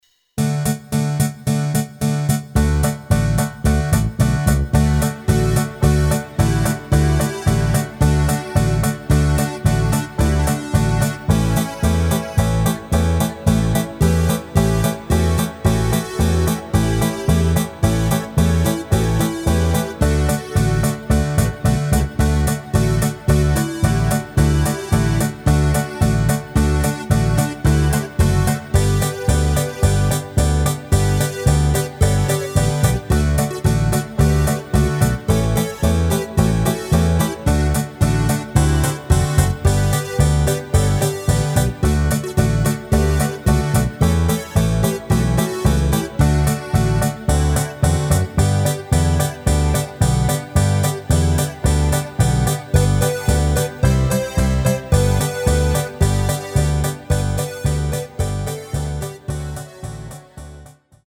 Rubrika: Pop, rock, beat
- polka
Karaoke